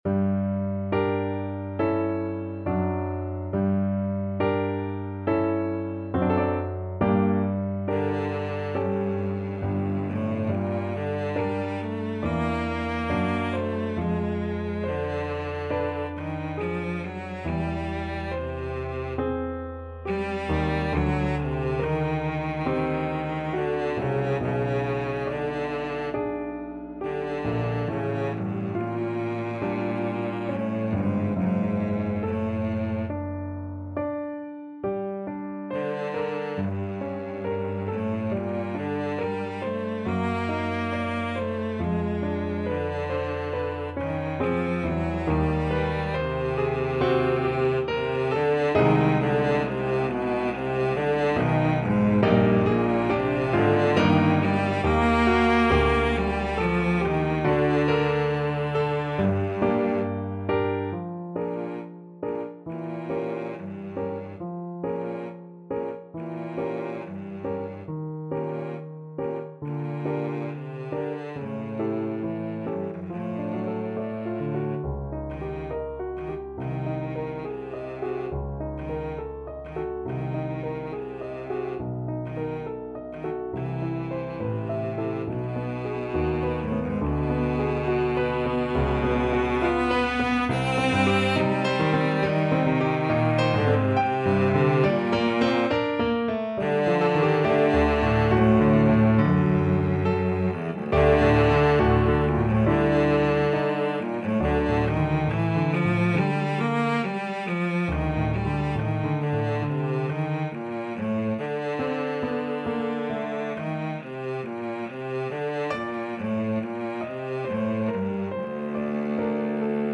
Cello version
~ = 69 Andante con duolo
4/4 (View more 4/4 Music)
Classical (View more Classical Cello Music)